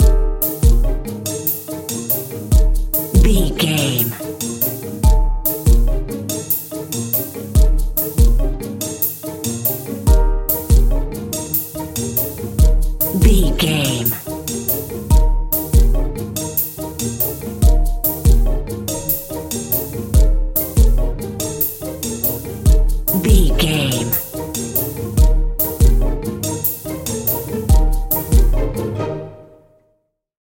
Ionian/Major
E♭
percussion
synthesiser
piano
strings
silly
circus
goofy
comical
cheerful
perky
Light hearted
quirky